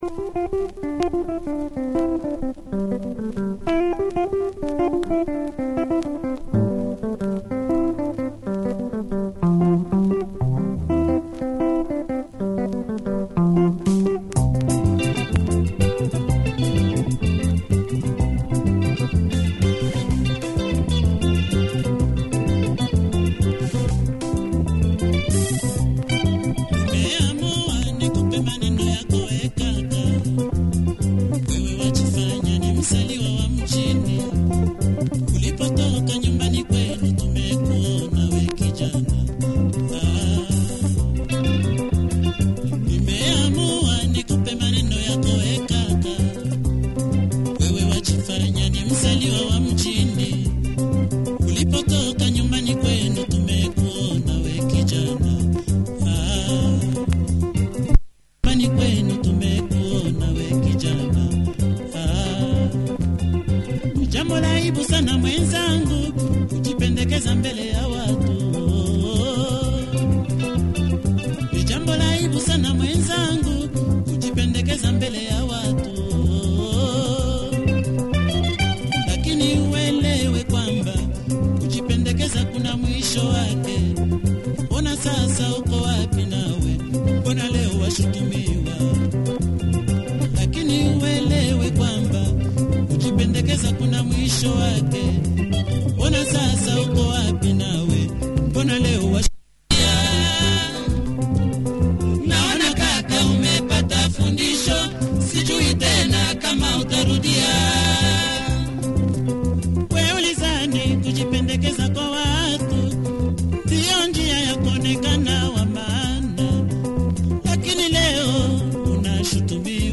Great solid soukous track in this burner